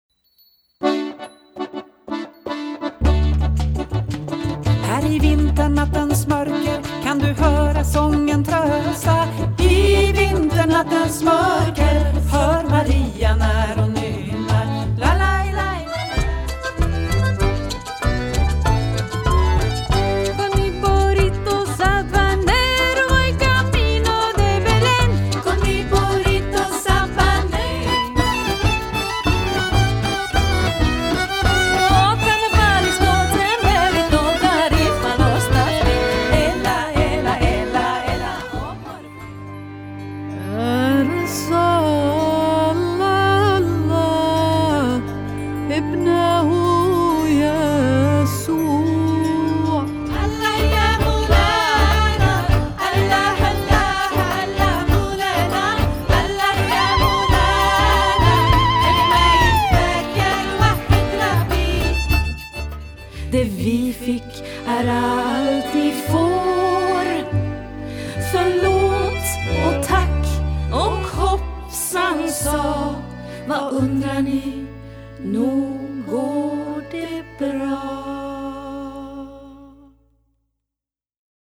sång, cello och slagverk
dragspel, sång och slagverk
violinquinton, sång och slagverk
kontrabas, sång och slagverk